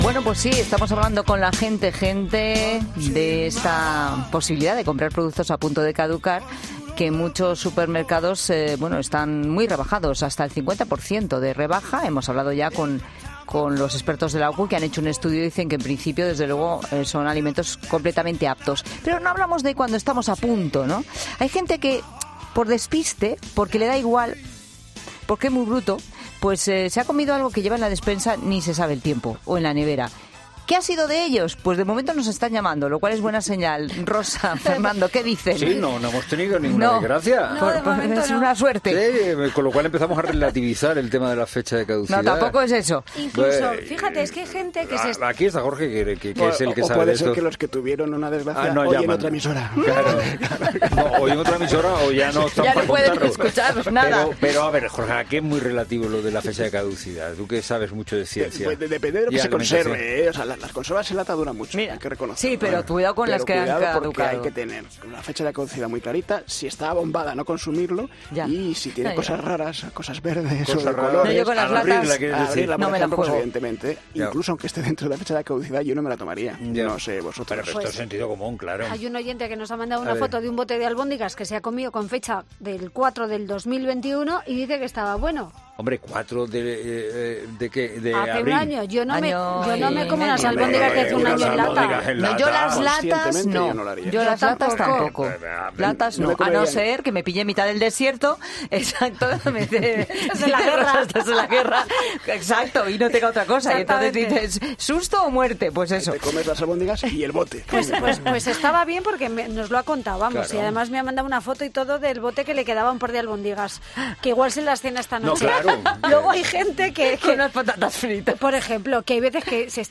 explicaba el oyente, antes de que estallaran las risas en el estudio.